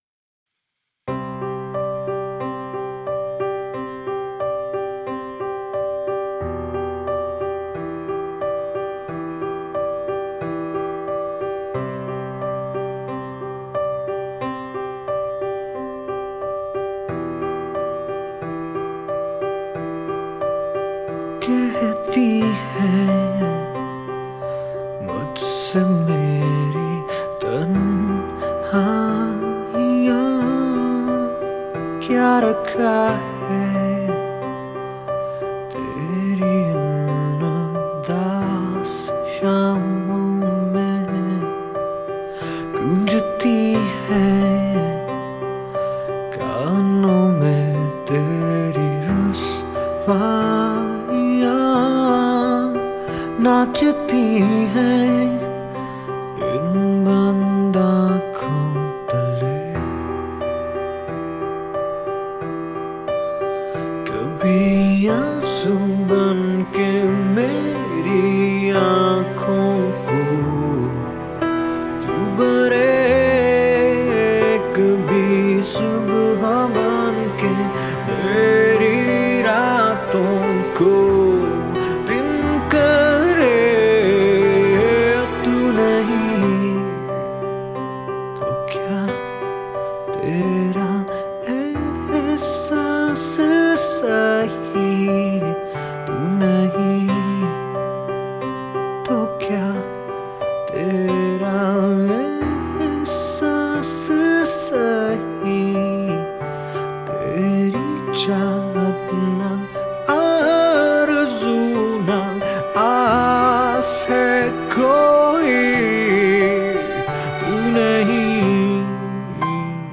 Vocals
Lead Guitars
Bass
Rhythm Guitars
Drums